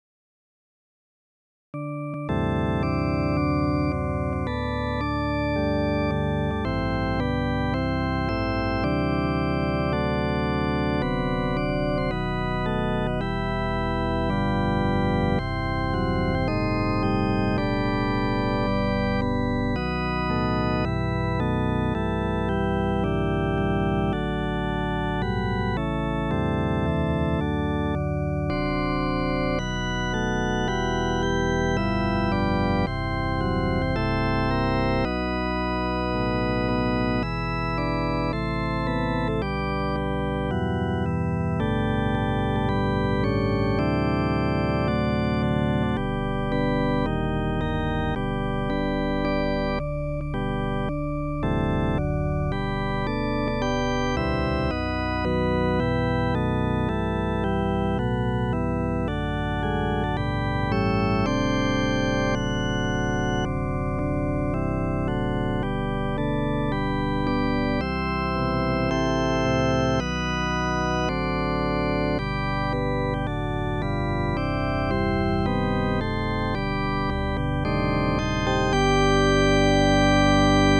Firm as the Mountains Around Us, organ freeform
Voicing/Instrumentation: Organ/Organ Accompaniment We also have other 5 arrangements of " Carry On ".